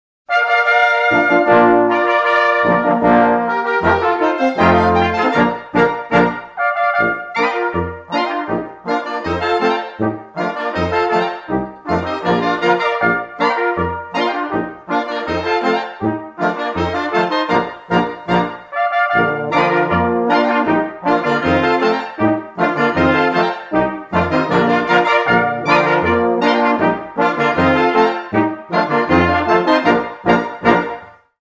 Ein wahrhaft bunter Reigen mit überlieferter und für den heutigen Gebrauch neugestalteter dörflicher Blasmusik!
Bairisch Polka - langsamer Polka mit Moll aus Litzlkirchen
Doerfliche_Blasmusik_Bairisch_Polka_22.mp3